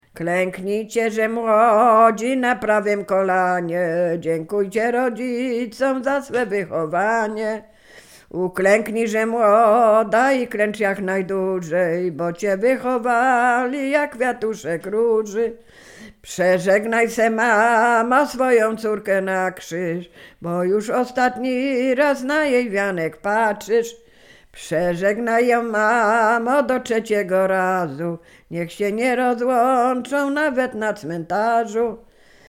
Sieradzkie
Liryczna
wesele weselne błogosławieństwo